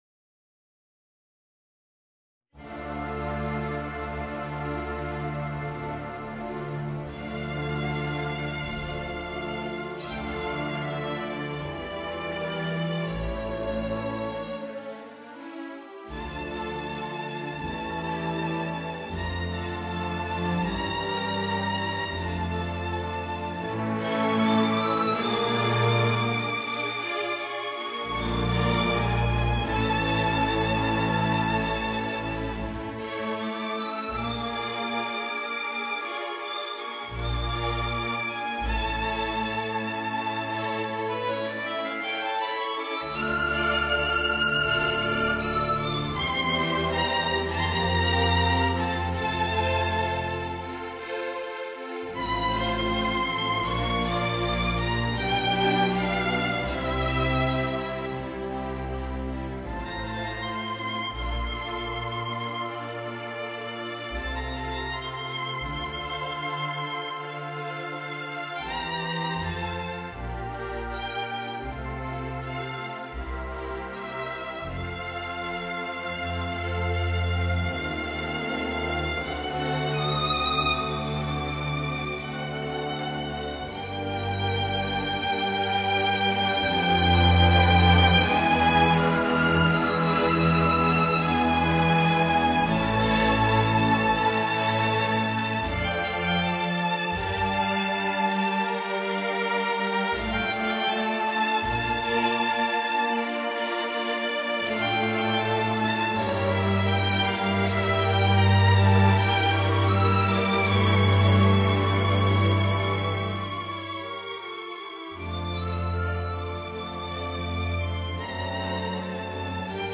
Voicing: Alto Saxophone and Orchestra